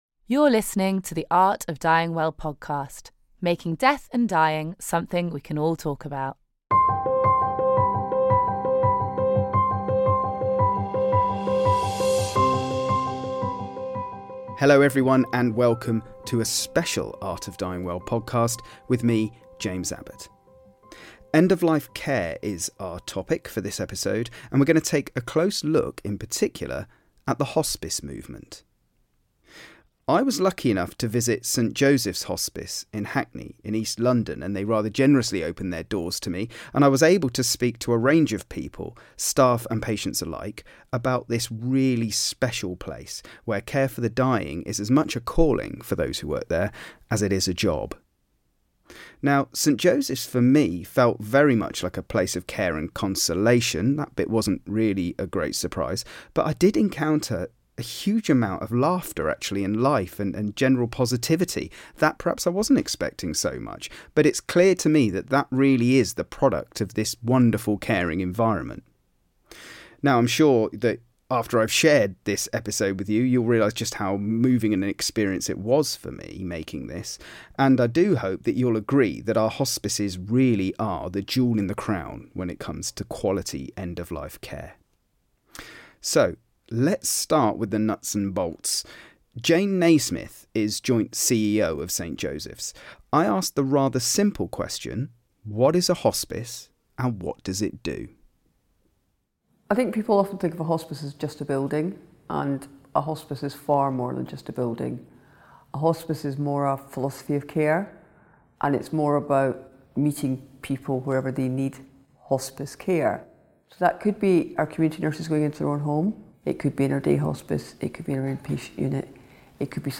It's time to challenge the stereotypes, bust the myths and go behind the scenes to find out just how hospices work and how they are the jewel in the crown when it comes to end of life care. Produced in partnership with the Centre for the Art of Dying Well, this special podcast sends us to a truly remarkable place - St Joseph’s Hospice in Hackney, East London.